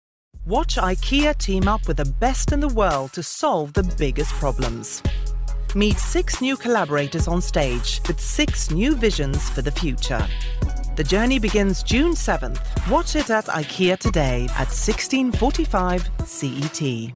Female
London, RP, Scandinavian
Voice description: Low, warm, authoritative, informative and sensual.
Swedish documentary.mp3
Microphone: JJ Labs